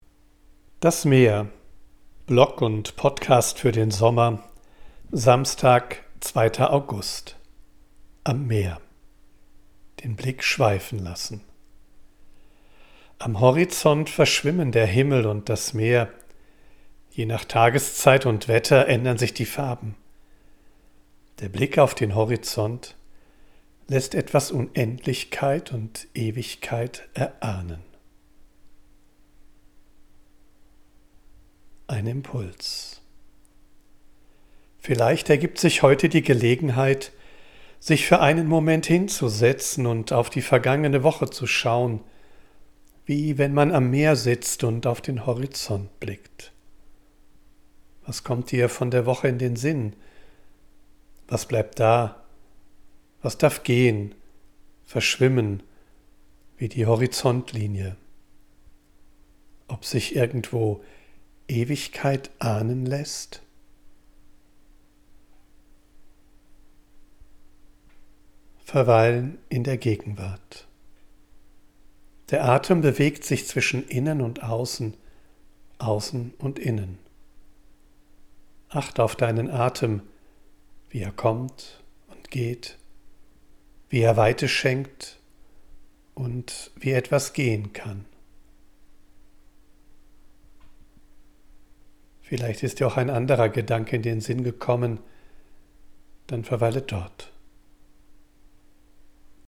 live. Ich bin am Meer und sammle Eindrücke und Ideen.
mischt sie mitunter eine echte Möwe und Meeresrauschen in die